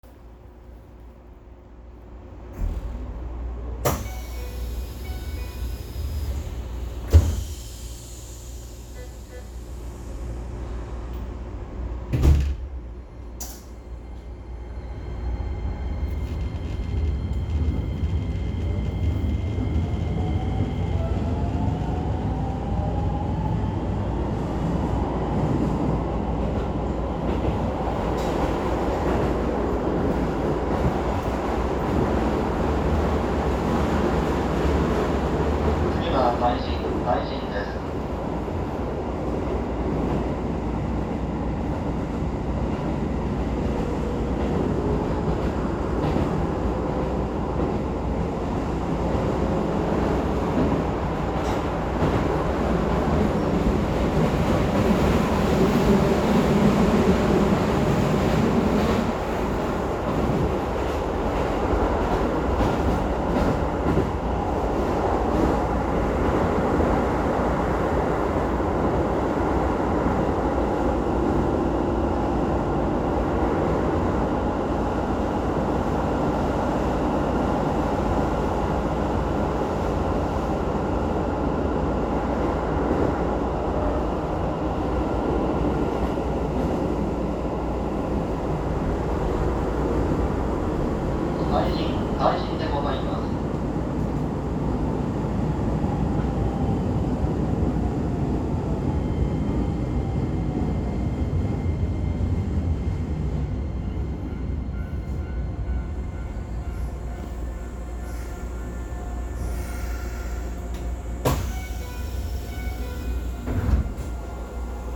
・3000形走行音
【京成本線】西船〜海神（1分55秒：3.5MB）…自動放送導入後
3000_Nishifuna-Kaijin.mp3